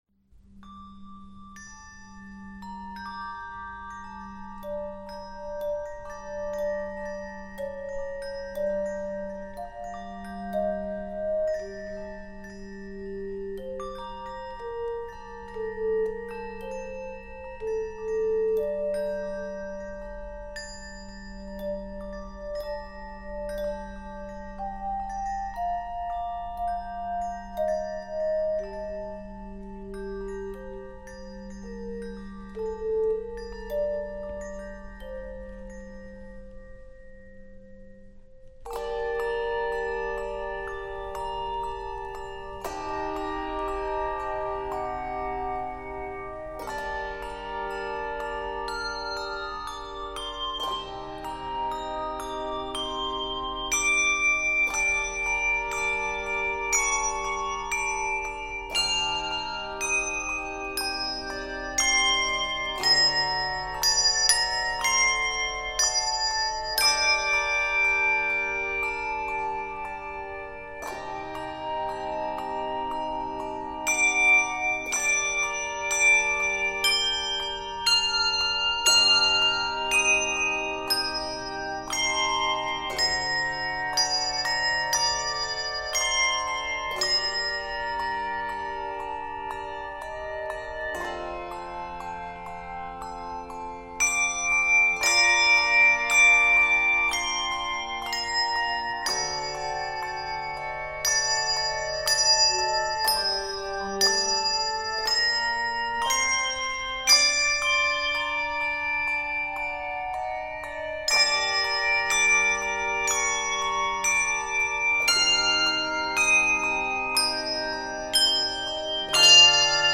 Key of Bb Major.